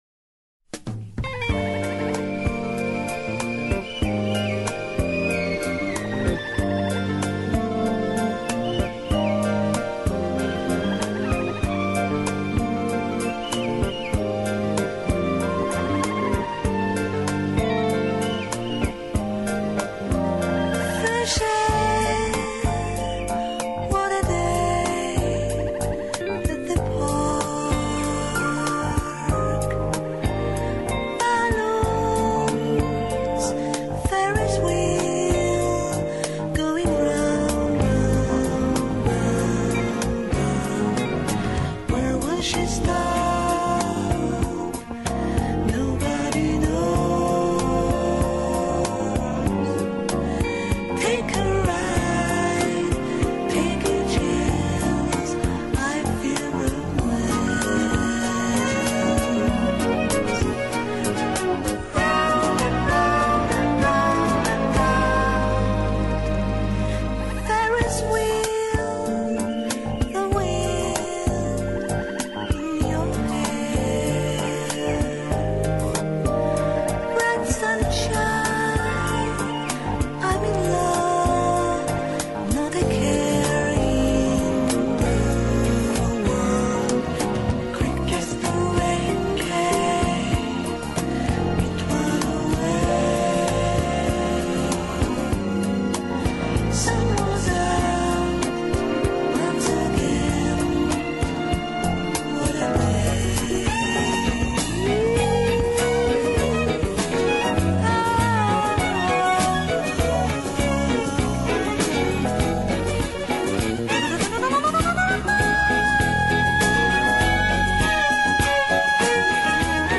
Polish jazz artist